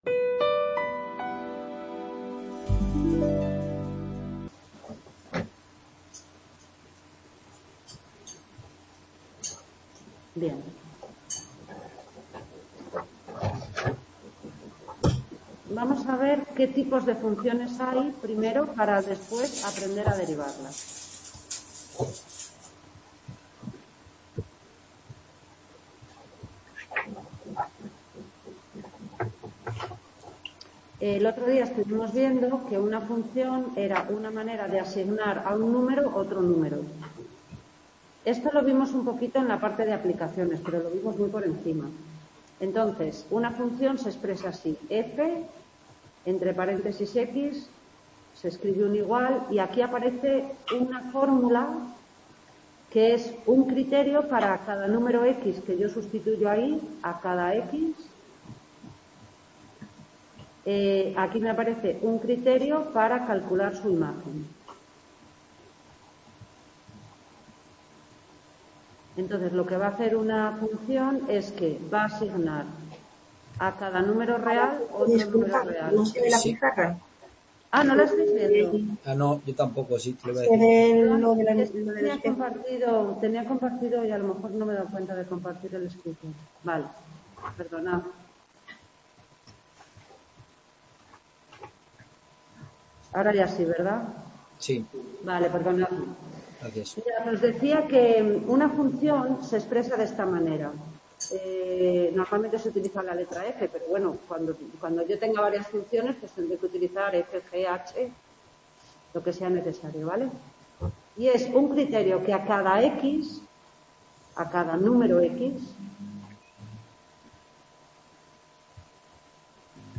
19_TUTORIA_ZAMORA_FUNCIONES_2 | Repositorio Digital